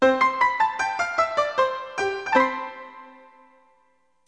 ding_end.mp3